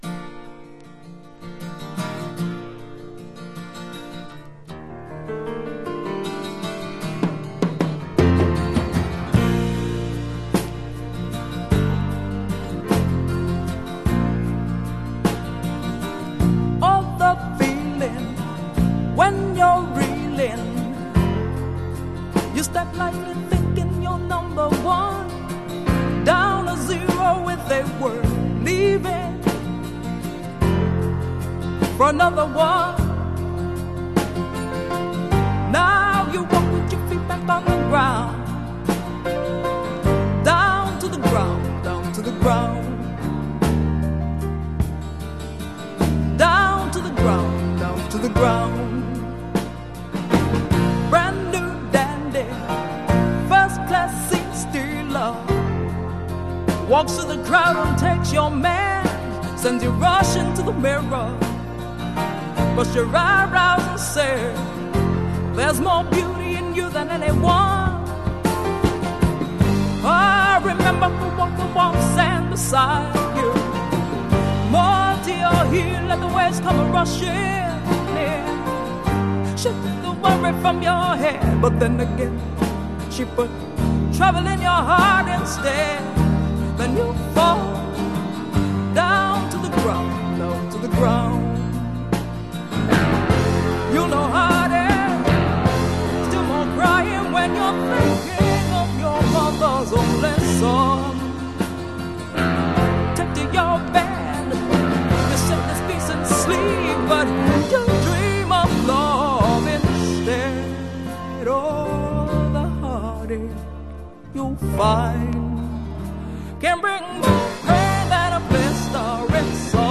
Genre: Folk Rock